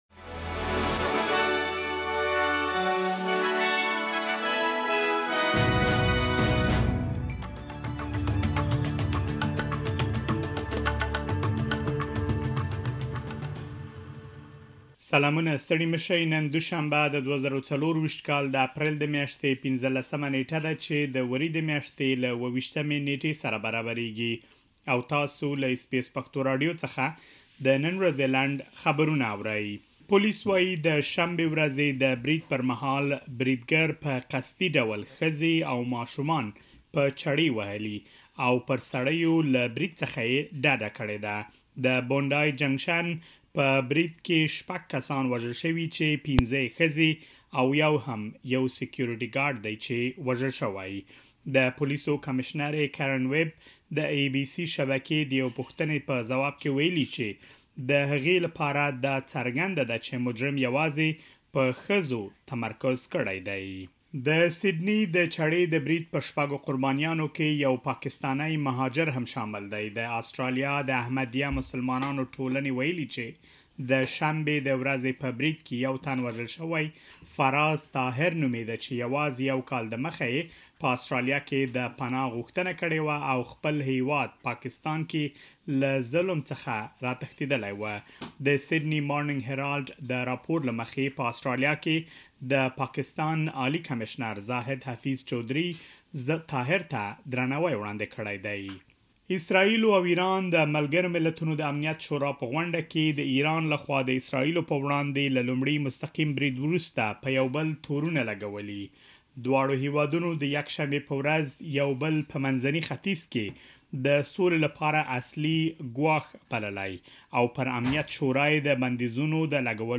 د اس بي اس پښتو راډیو د نن ورځې لنډ خبرونه|۱۵ اپریل ۲۰۲۴
اس بي اس پښتو راډیو د نن ورځې لنډ خبرونه دلته واورئ.